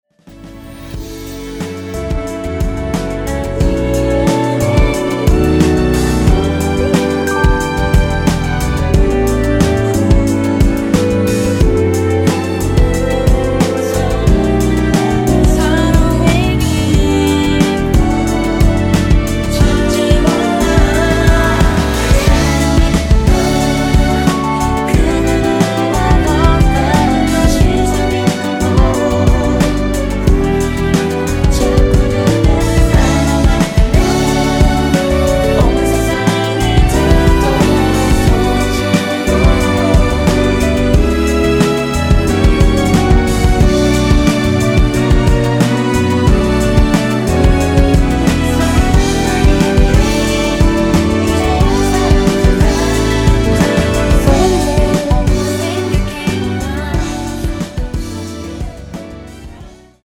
원키에서(-1)내린 멜로디와 코러스 포함된 MR입니다.(미리듣기 확인)
D
앞부분30초, 뒷부분30초씩 편집해서 올려 드리고 있습니다.
중간에 음이 끈어지고 다시 나오는 이유는